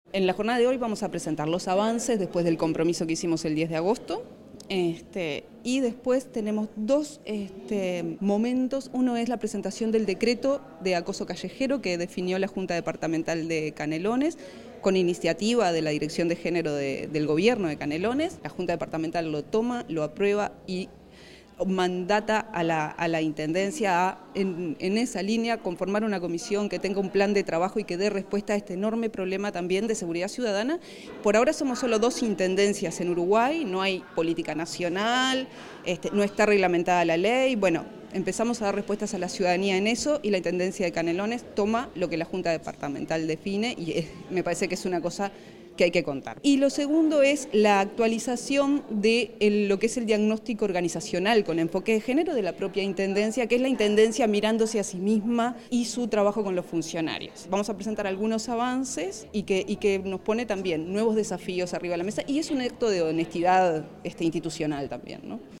La actividad se desarrolló en la Sala Beto Satragni del Complejo Cultural Politeama – Teatro Atahualpa del Cioppo, en presencia del Secretario General de la Intendencia de Canelones, Dr. Esc. Francisco Legnani, la Pro Secretaria General, As. Soc. Silvana Nieves, el Pro Secretario General, Marcelo Metediera, la Directora de Género y Equidad, Nohelia Millán, demás directoras y directores de la Intendencia, alcaldesas y alcaldes y otras autoridades departamentales y locales.